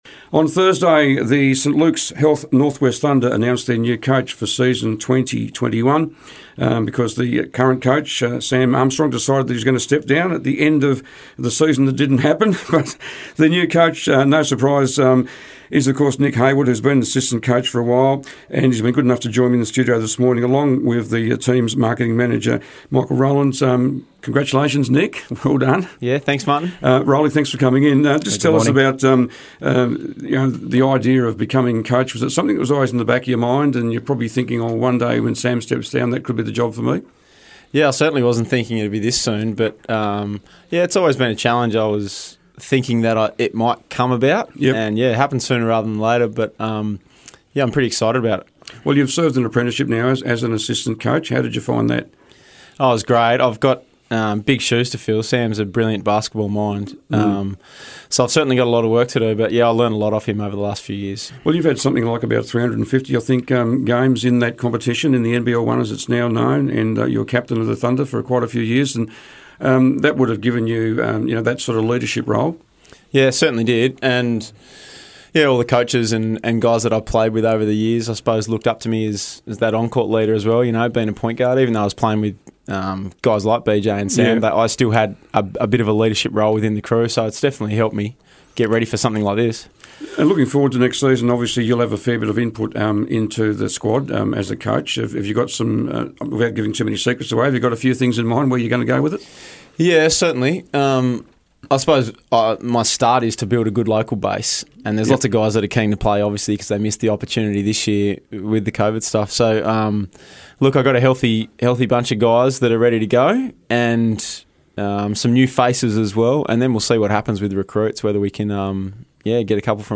in the studio this morning